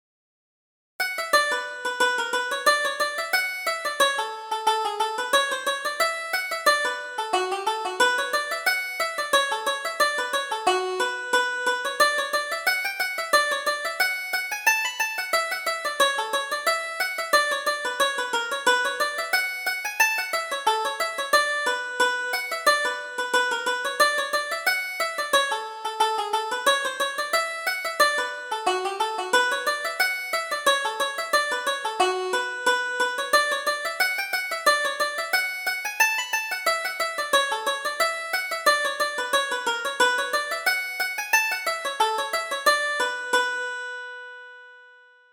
Reel: The Pleasures of Home